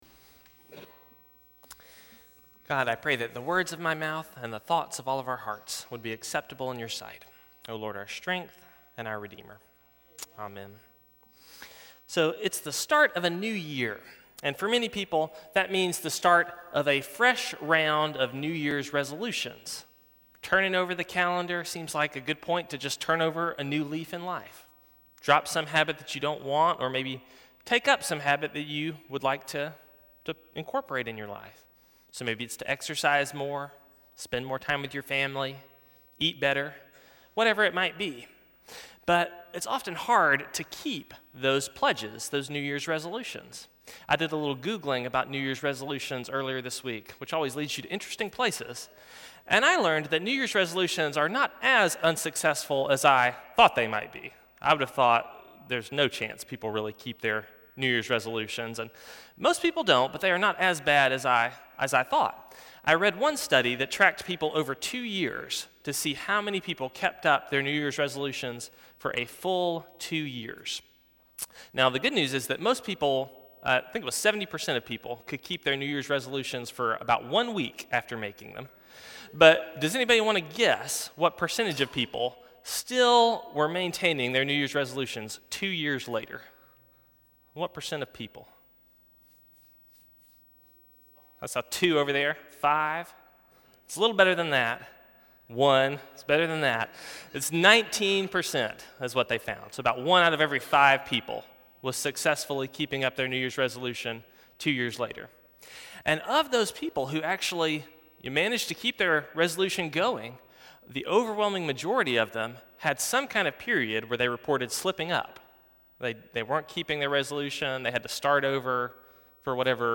Sermons | Forest Hills Baptist Church